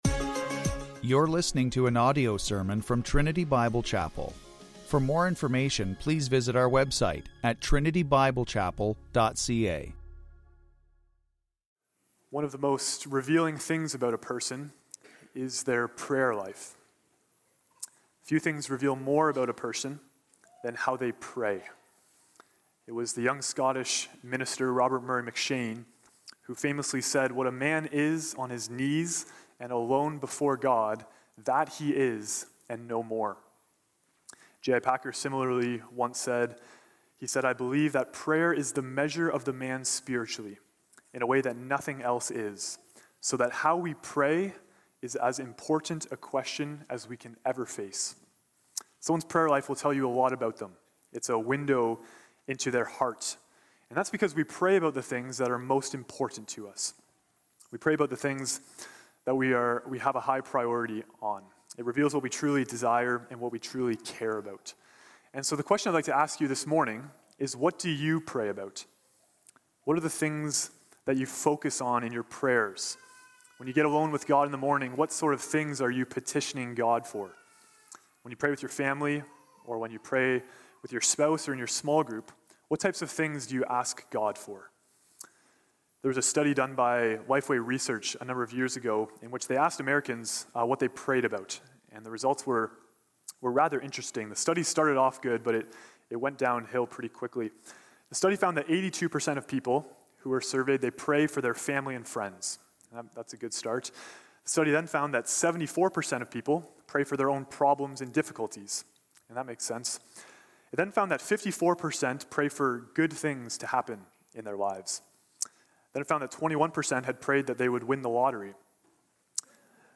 Series: Single Sermons Topics: prayer , sanctification